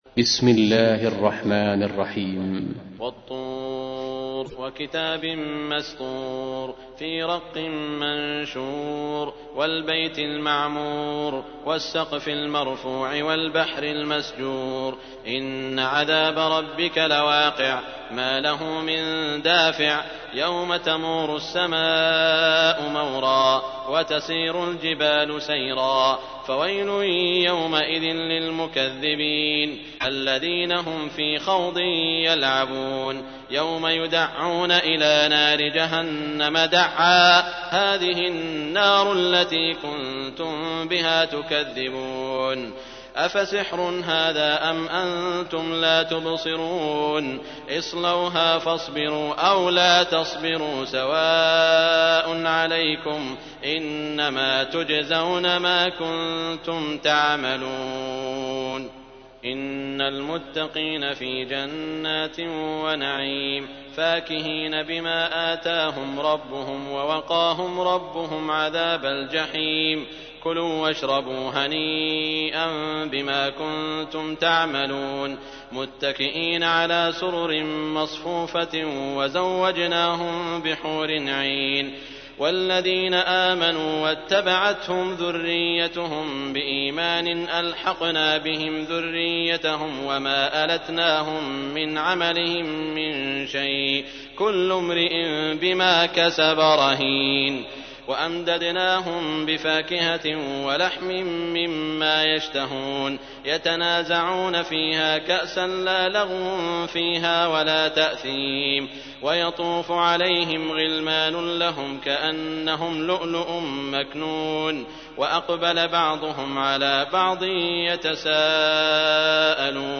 تحميل : 52. سورة الطور / القارئ سعود الشريم / القرآن الكريم / موقع يا حسين